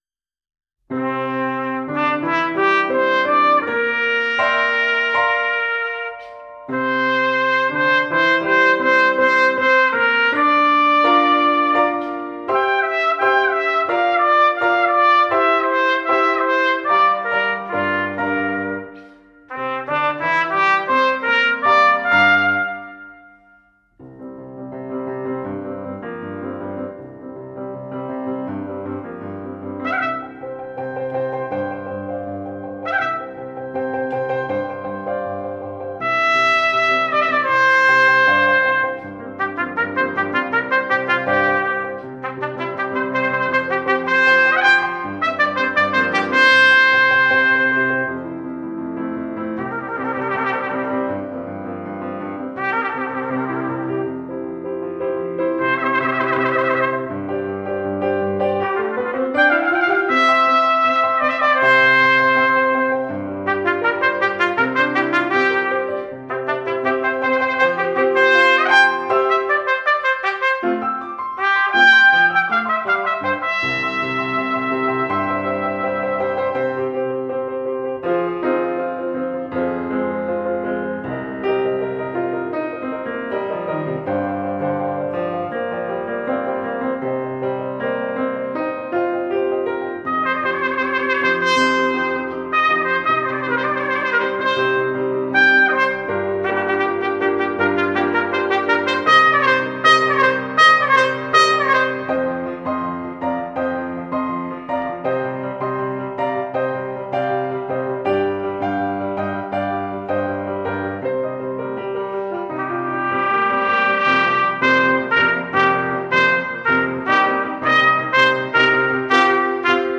for Trumpet and String Orchestra (2008)